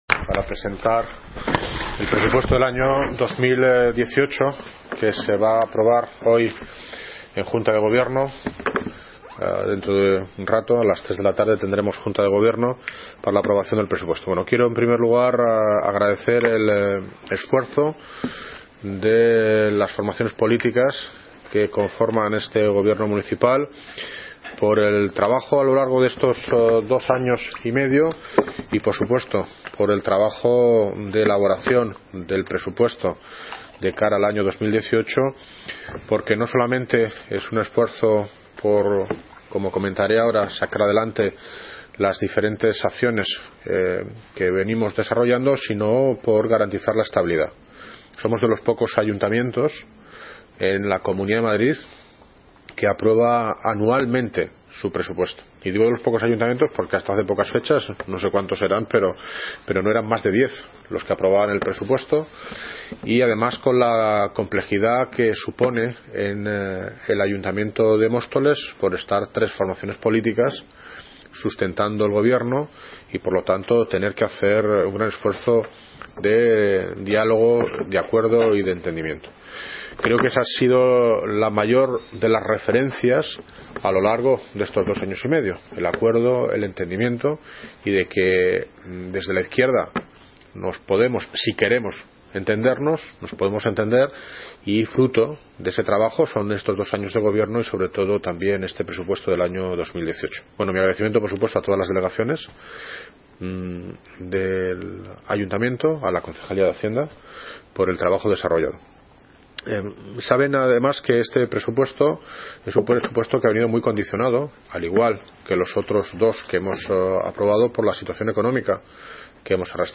Audio - David Lucas (Alcalde de Móstoles) Presentación Presupuestos 2018
Audio - David Lucas (Alcalde de Móstoles) Presentación Presupuestos 2018.mp3